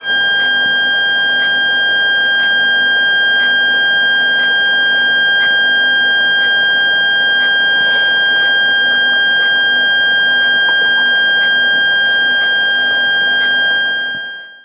Основной тон находится в районе 1.6 кГц. При максимальном повороте резистора (самой яркой подсветке) инструмент "поёт" как бормашина.
В прикрепленном файле - тот самый нойз, снятый мной возле самой панели Вояджера Шуром KSM141......